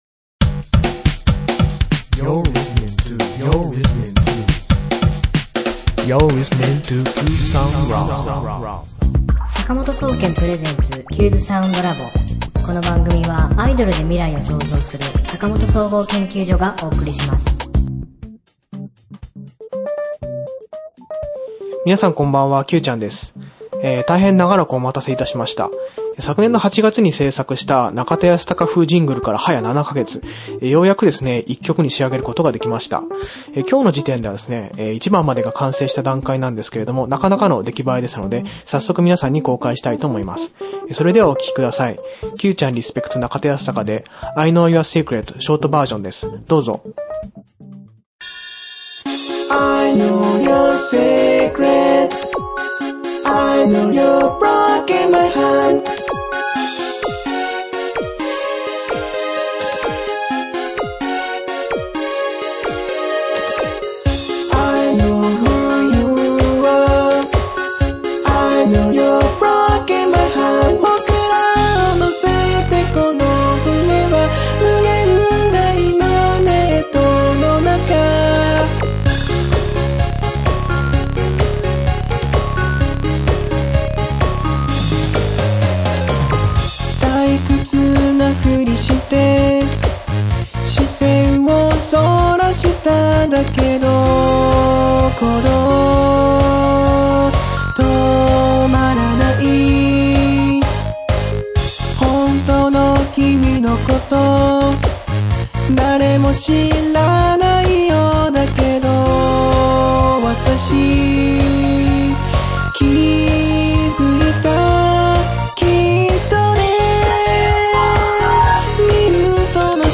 今週のテーマ：中田ヤスタカ風ジングルが曲になりましたよ！
今週の挿入歌